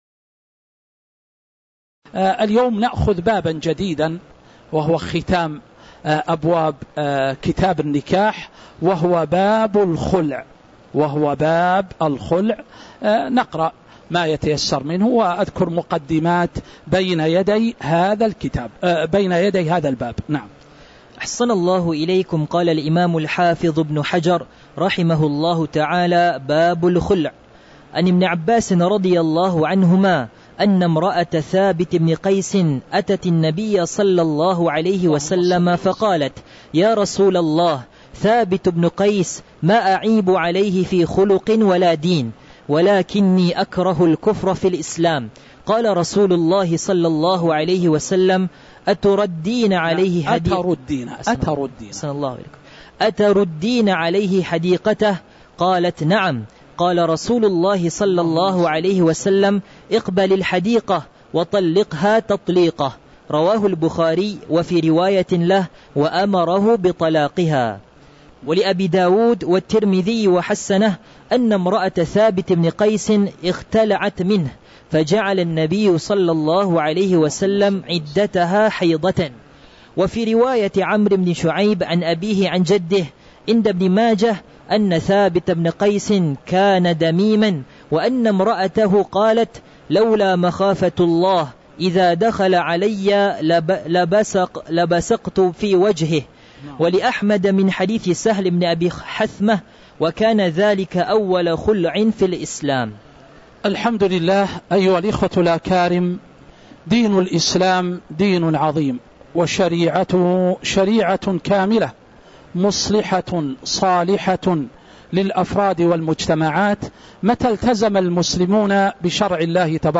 تاريخ النشر ١٦ شوال ١٤٤٦ هـ المكان: المسجد النبوي الشيخ